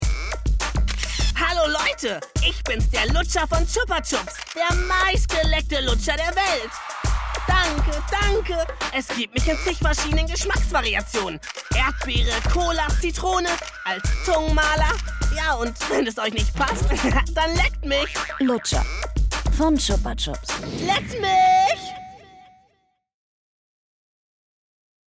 sehr variabel
Jung (18-30)
Commercial (Werbung), Trick, Comedy